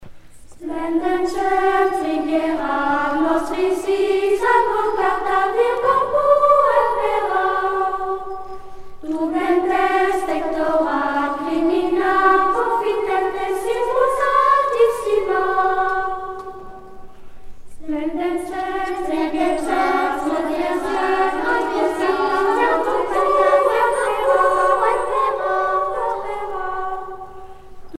canon caça
Pièce musicale éditée